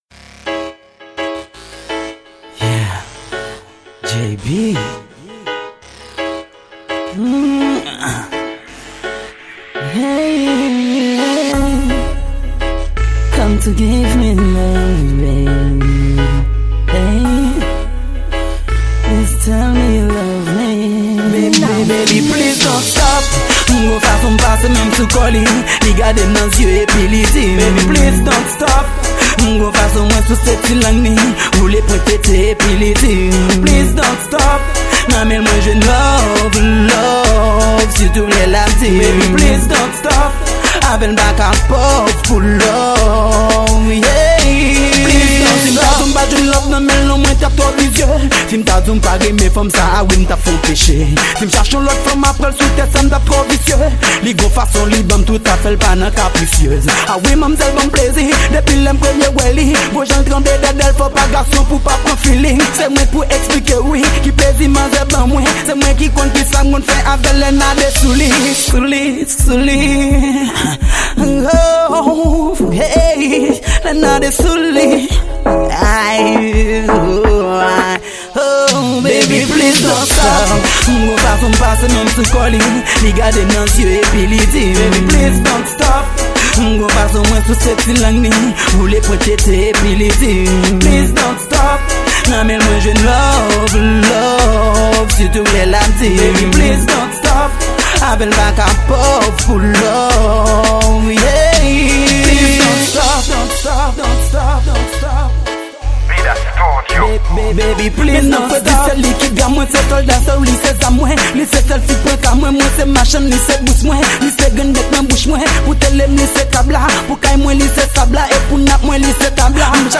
Genre: Riddim.